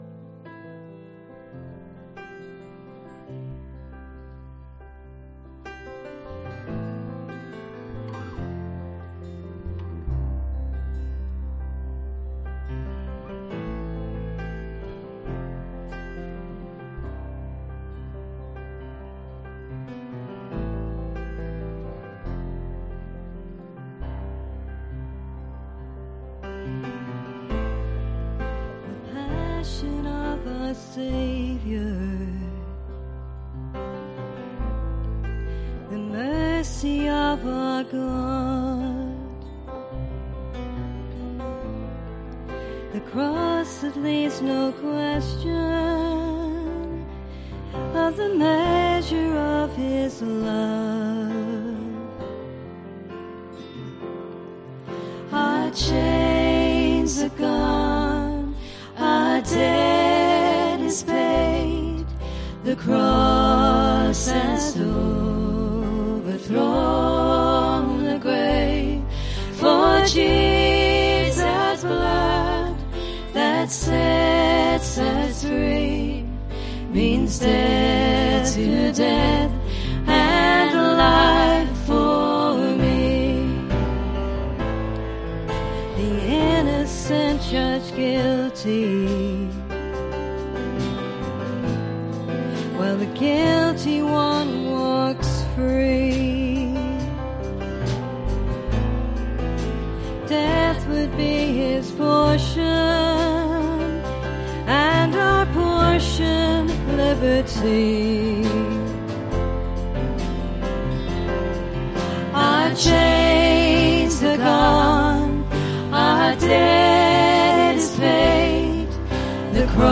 Ministry Song https
Service Type: pm